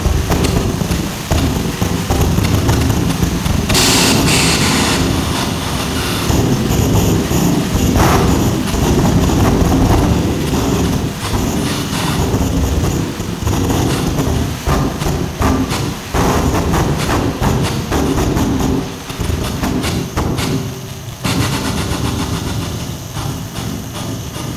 live
piston cylinder sound installation and performance